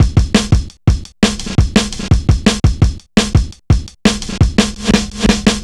Index of /90_sSampleCDs/Zero-G - Total Drum Bass/Drumloops - 3/track 57 (170bpm)